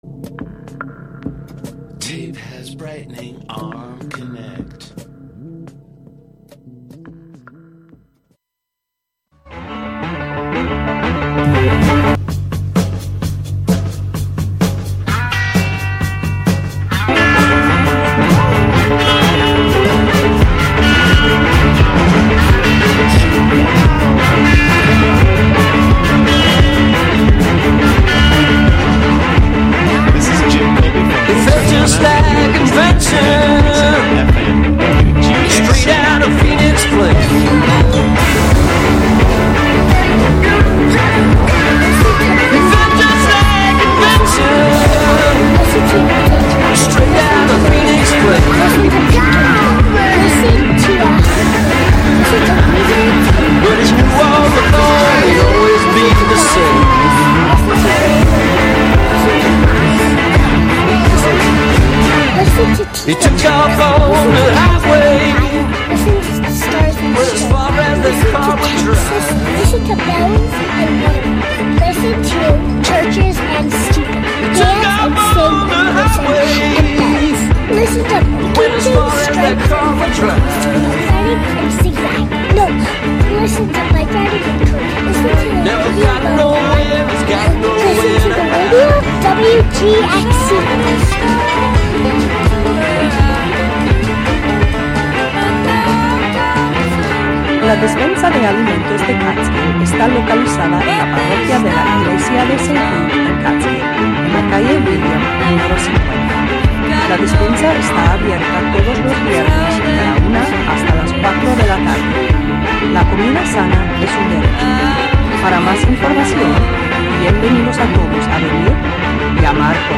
Settle in with a cup of coffee, tea, or bourbon and join us in the conversation.